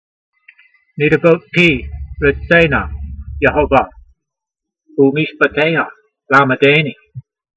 needevot pee re'tsay-na yehovah, oo'meeshpateyha lamedaynee